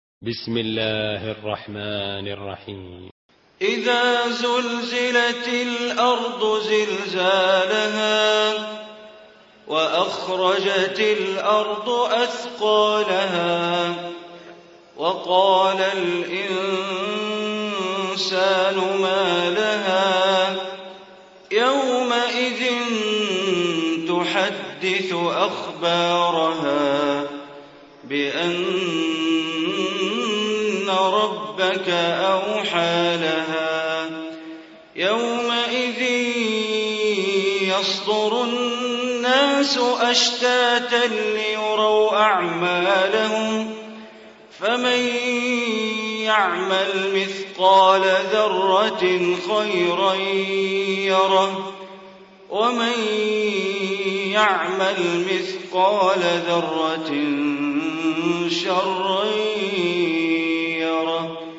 Surah Zalzalah Recitation by Sheikh Bandar Baleela
Surah Zalzalah, listen online mp3 tilawat / recitation in Arabic recited by Imam e Kaaba Sheikh Bandar Baleela.